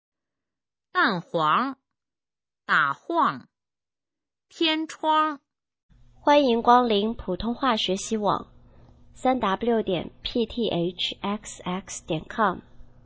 普通话水平测试用儿化词语表示范读音第6部分